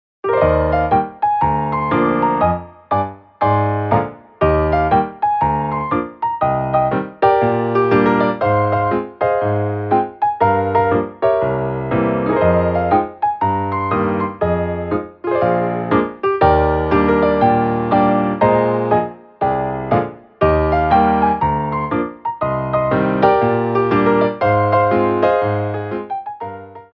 MEDIUM TEMPO